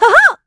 Estelle-Vox_Attack2.wav